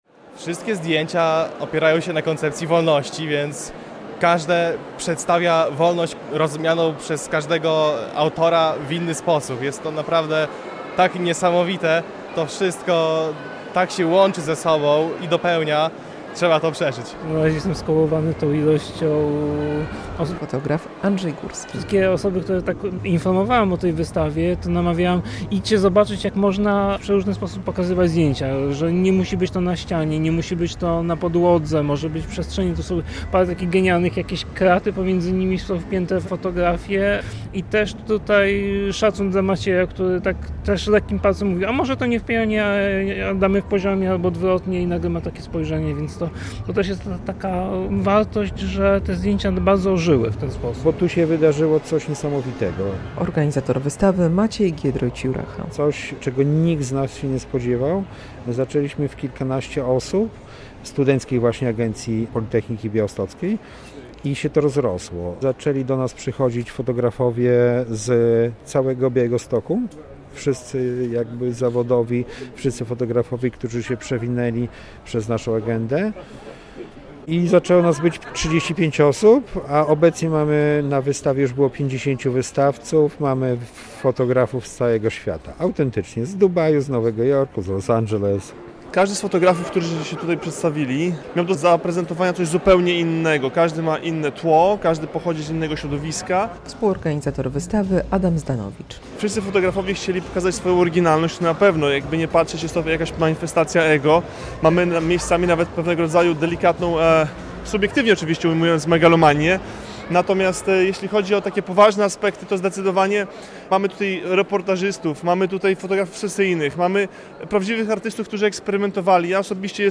Wystawa "Wolność" w dawnej hali mięsnej przy ulicy Bema w Białymstoku - relacja